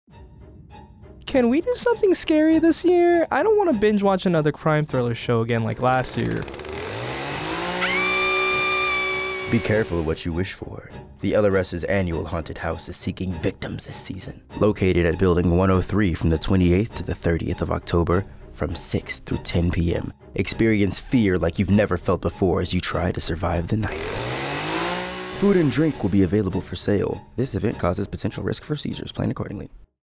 hauntedchainsawScreamhorrorhouse